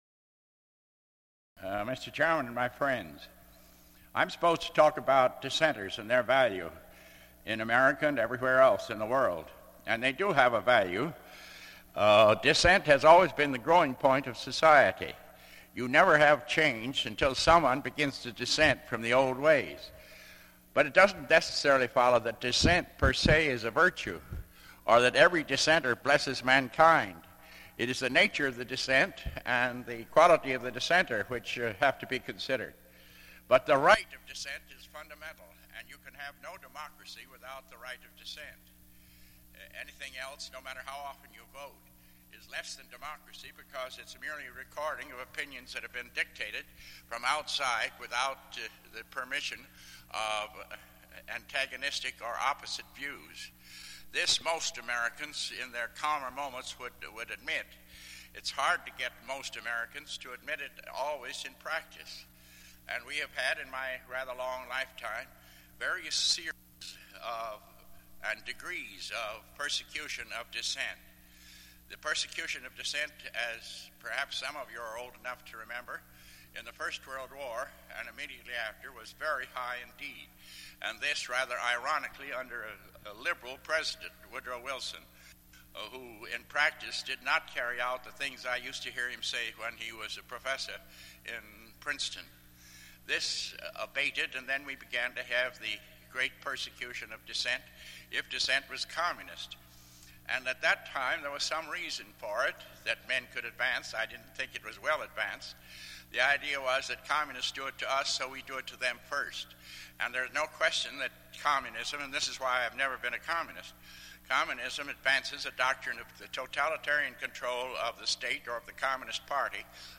Minister, socialist and Presidential Candidate Norman Thomas gave a lecture at Fountain Street Church in 1965